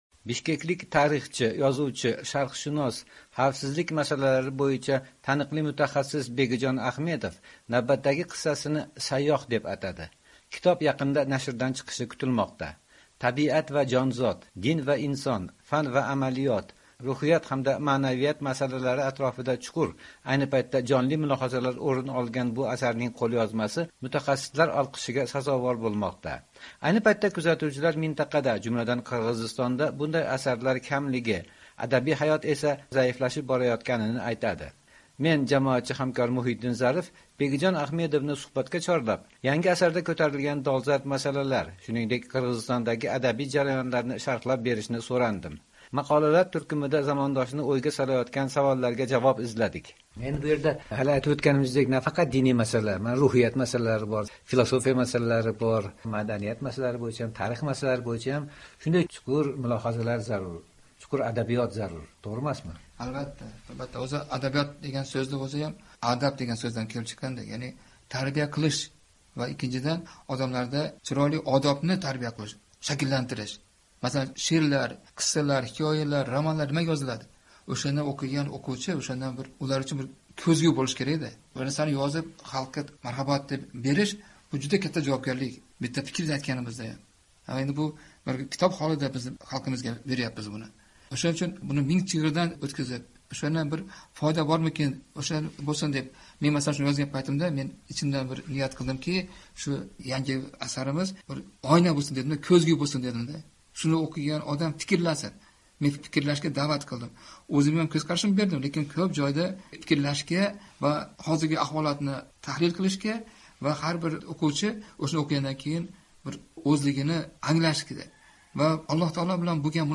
suhbat (3-qism)